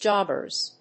/ˈdʒɑbɝz(米国英語), ˈdʒɑ:bɜ:z(英国英語)/